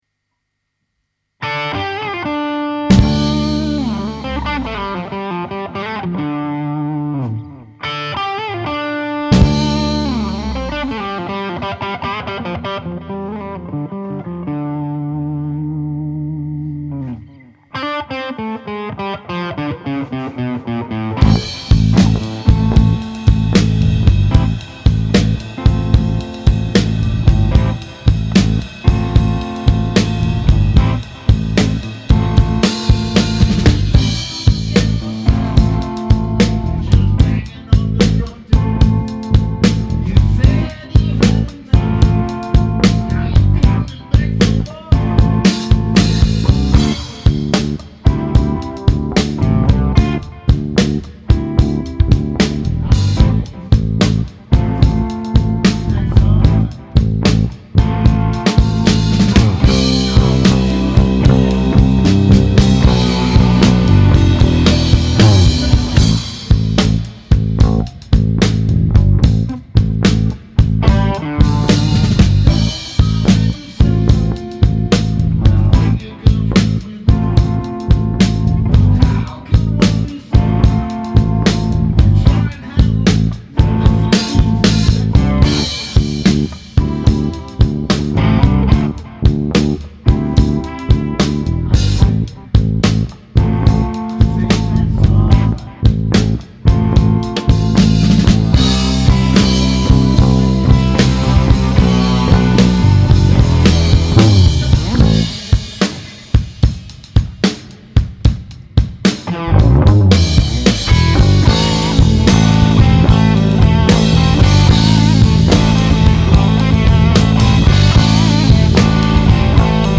TI Flats drauf und den Quarterpound als Pickup mit 500er Potis, man glaubt es nicht aber sowas von geiler Sound.
Gesang zurückgenommen, da hört man den Bass über Kopfhörer echt gut.